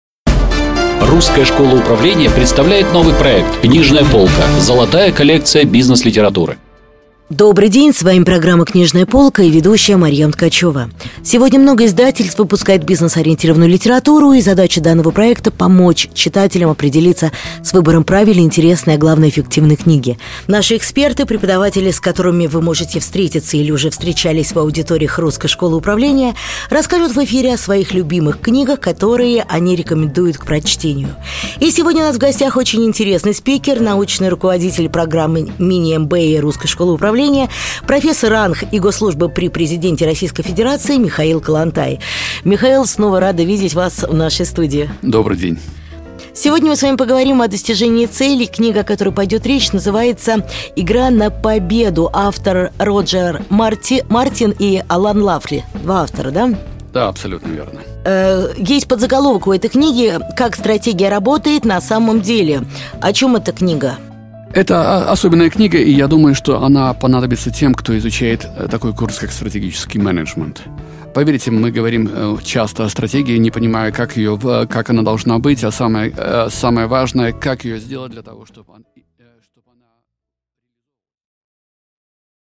Аудиокнига Обзор книги Р. Мартина и А. Лафли «Игра на победу» | Библиотека аудиокниг